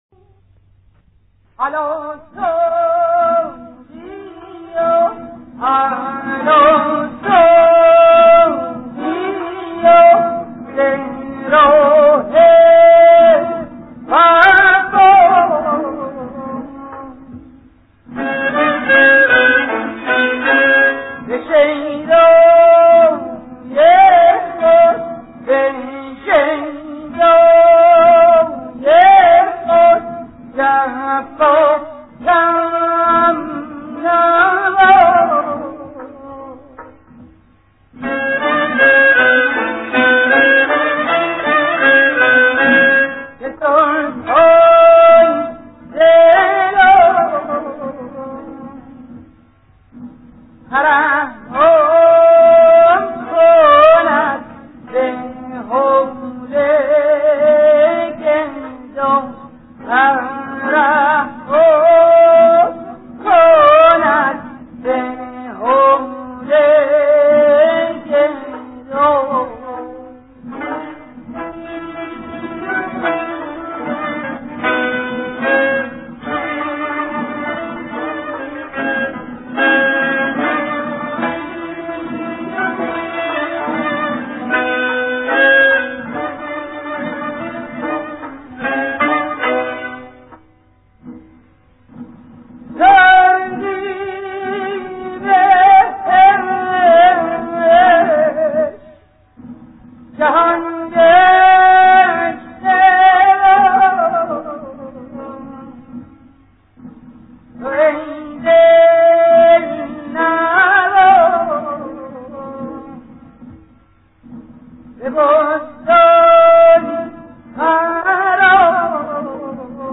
مایه: ابو عطا